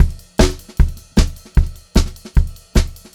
152SPCYMB2-R.wav